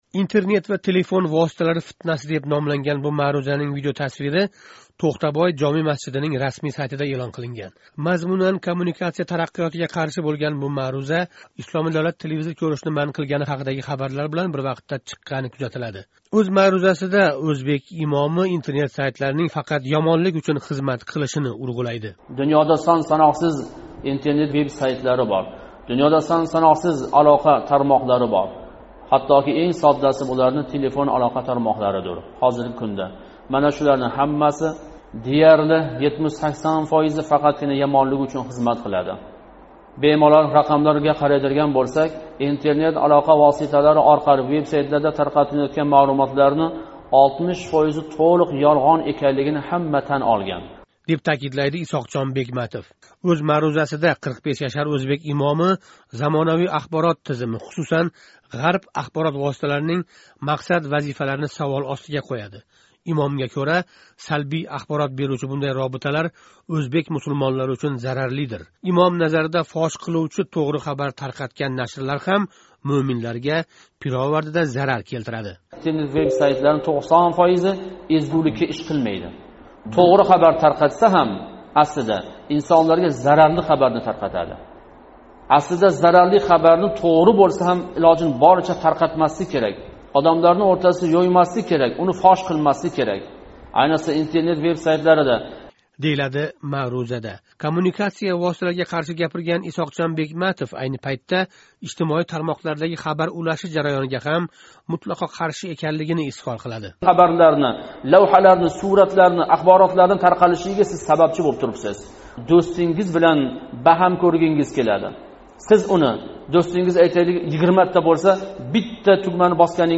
Мазмунан коммуникация тараққиëтига қарши бўлган бу мавъиза “Исломий Давлат” экстремист гуруҳи телевизор кўришни ман қилган бир пайтда Интернетга жойлангани кузатилди.
Ўзбек имоми ўз маърузаси сўнгида мусулмонларга қарата насиҳат қилиб: “Телефонни умуман ташланг¸ Интернетдан фойдаланишни умуман йиғиштириб қўйинг”, деб таъкидлайди.